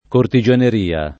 cortigianeria [ korti J aner & a ] s. f.